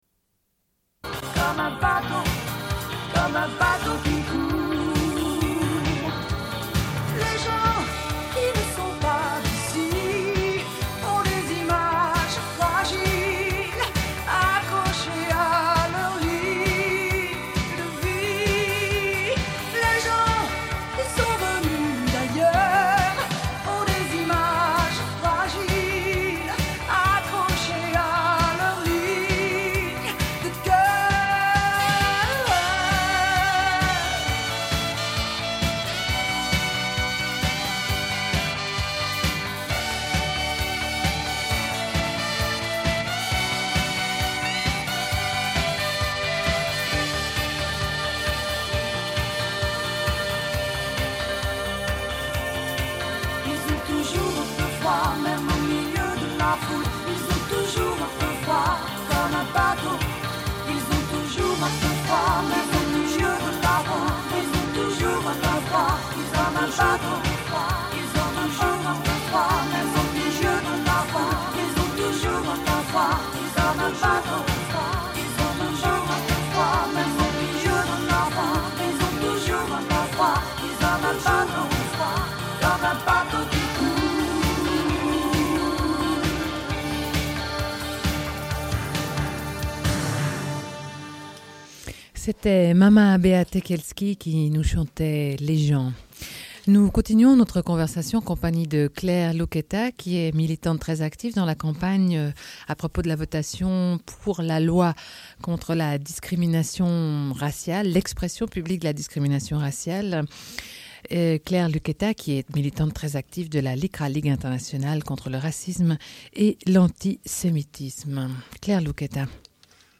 Une cassette audio, face B29:02